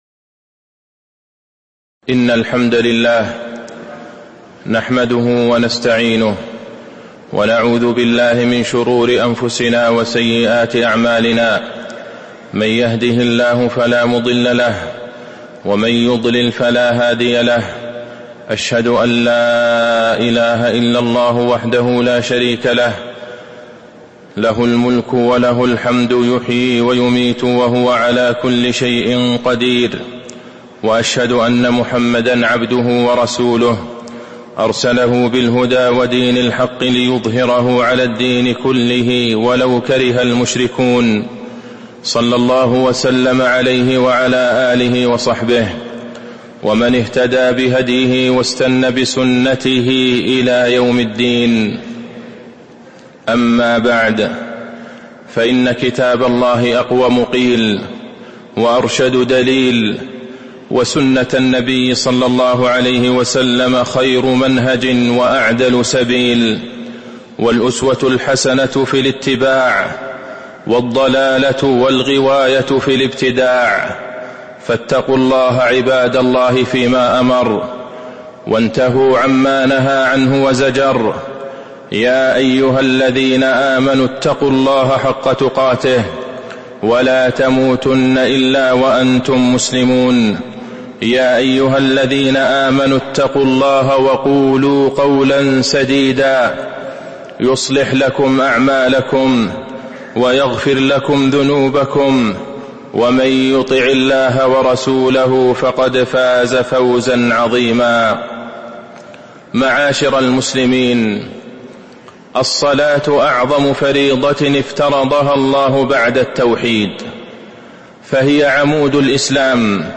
تاريخ النشر ٢٢ ربيع الثاني ١٤٤٦ هـ المكان: المسجد النبوي الشيخ: فضيلة الشيخ د. عبدالله بن عبدالرحمن البعيجان فضيلة الشيخ د. عبدالله بن عبدالرحمن البعيجان الصلاة وماملكت أيمانكم